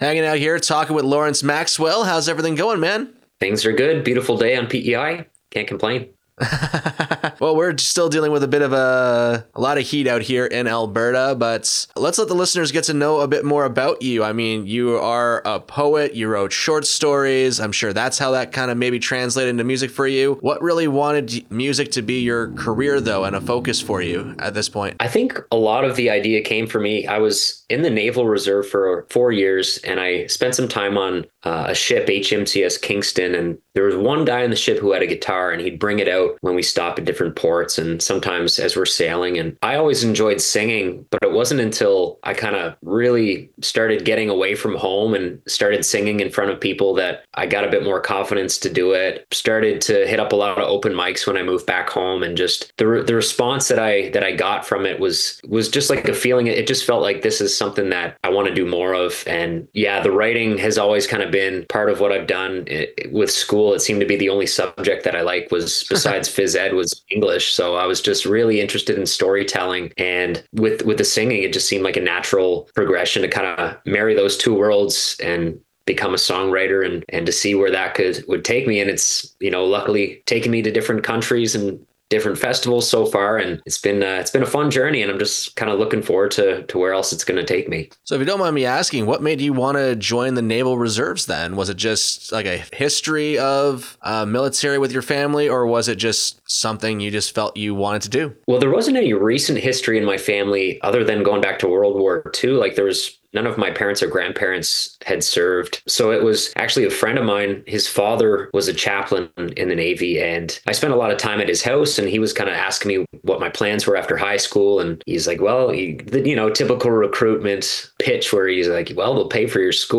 Rising Stars Headliner Interview